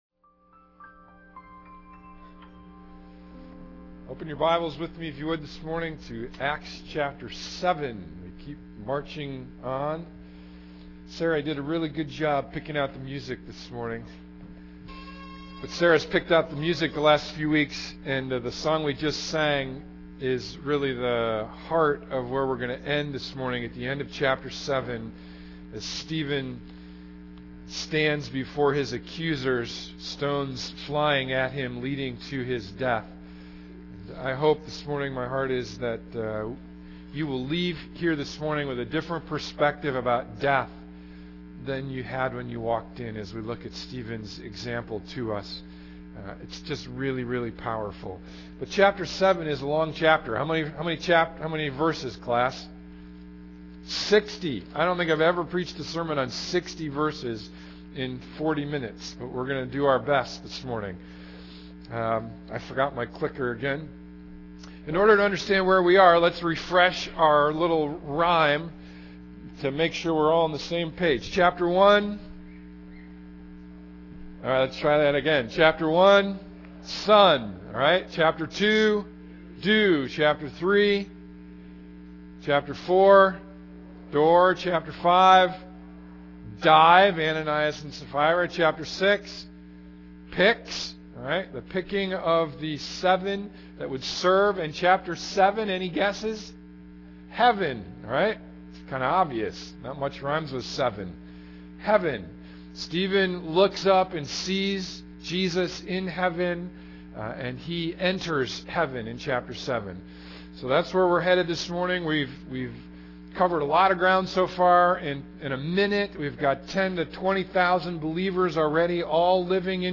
Sunday Morning Book of Acts